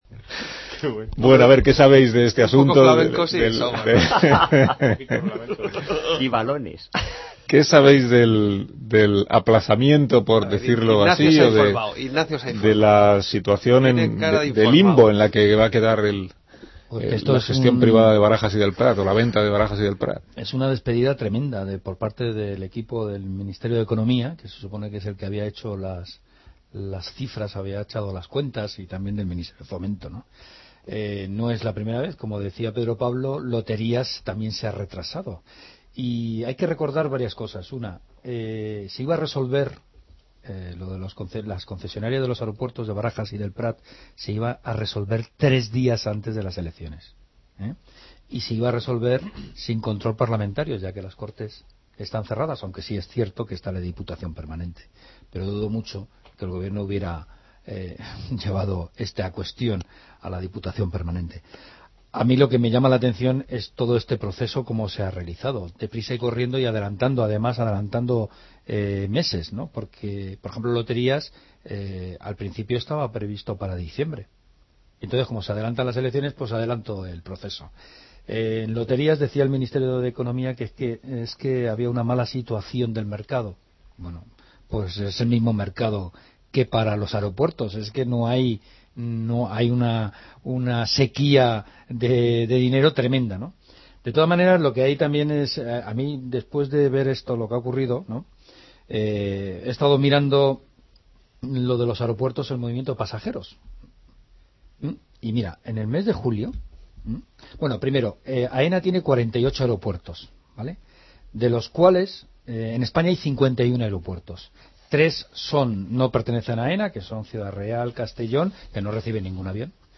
La Brújula de Onda Cero comenzó el pasado día 14 con el monólogo de Carlos Alsina: Barajas y El Prat. El congelador de Ignacio Camacho también se centra en el tema de la privatización de los aeropuertos. La tertulia, con David Gistau, Toni Bolaño y Pilar Cernuda, en la que comentan también el retraso de la privatización de los aeropuertos de Barajas y el Prat y las razones ocultas.